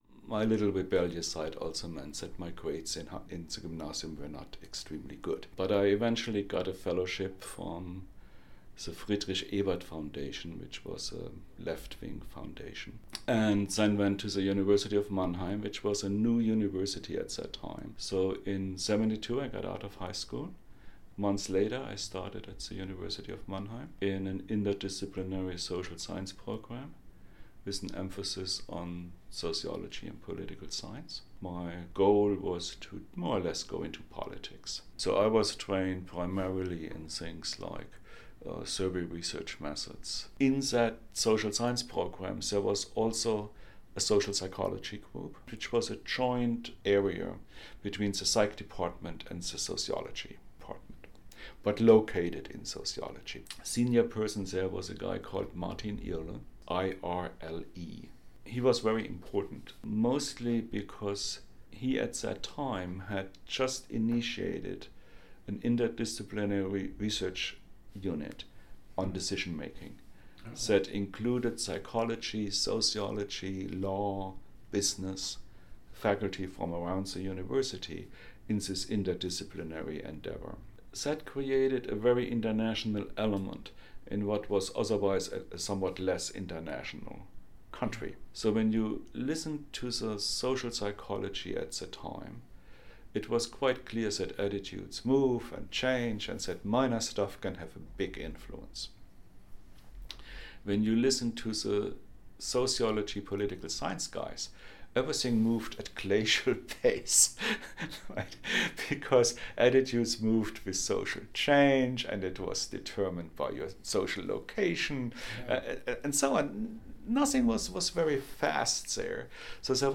With some wry humor and amusement, Dr. Schwarz recalls his formative introduction to Psychology, cross-disciplinary Social Science research, and the methods of experimentation during his education at the University of Mannheim (Dipl. of Sociology in 1977, D.Phil in Soc & Psych in 1980).
All of this and the historical growth of the Social Indicators movement is recalled in this next excerpt from our conversation (about 5 min):